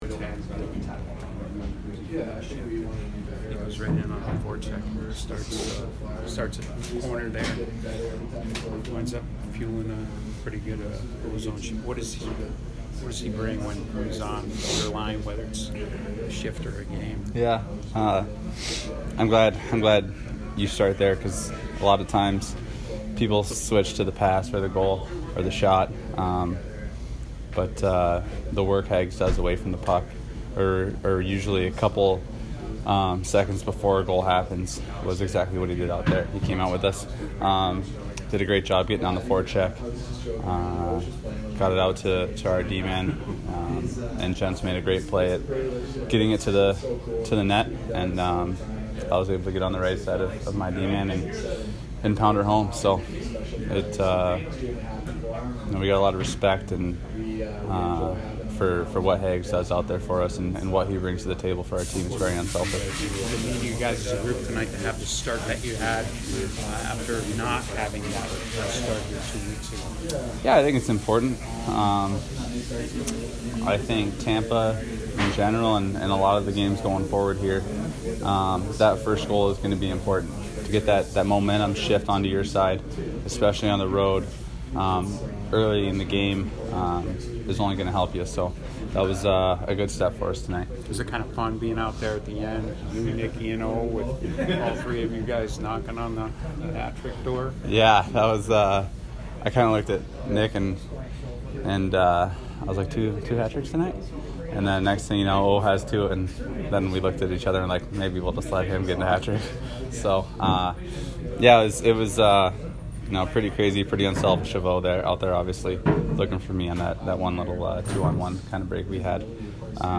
TJ Oshie post-game 3/30